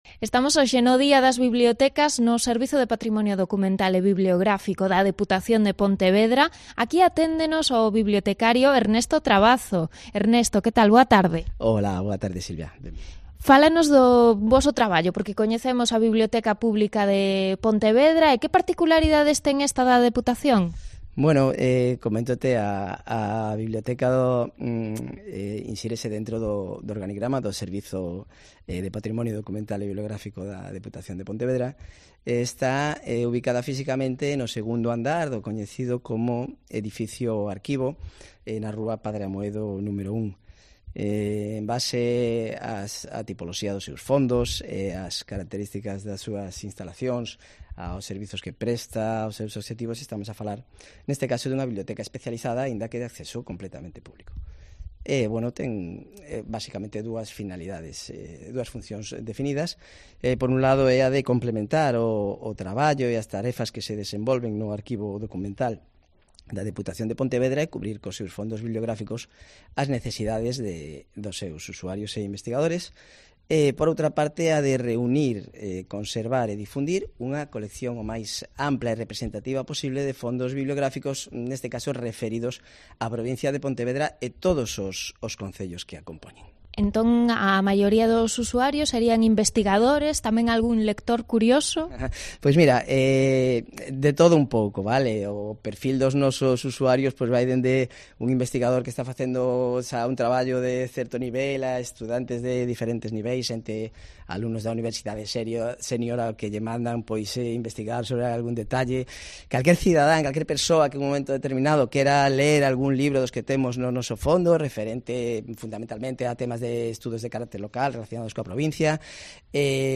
AUDIO: Entrevista